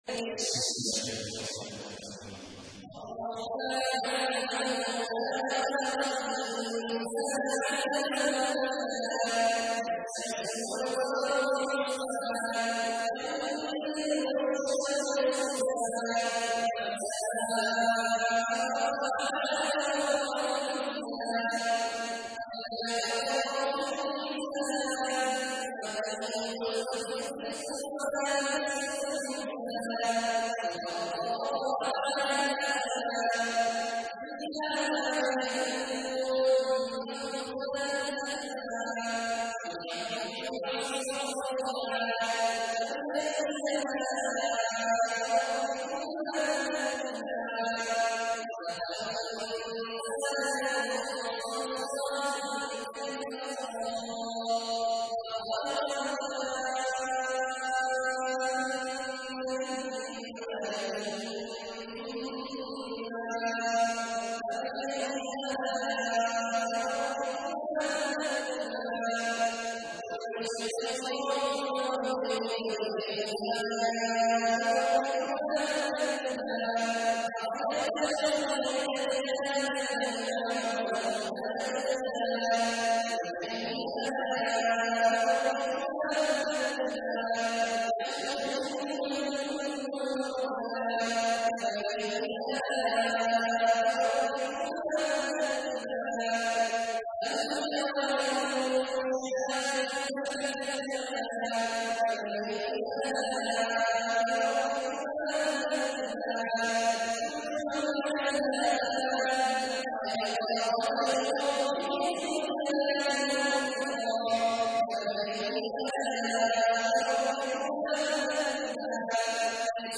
تحميل : 55. سورة الرحمن / القارئ عبد الله عواد الجهني / القرآن الكريم / موقع يا حسين